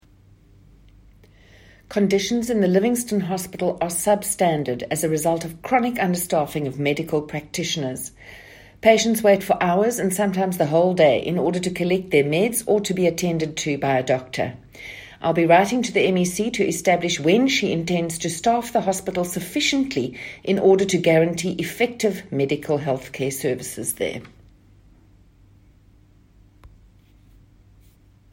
Note to Editors: Please find attached soundbites in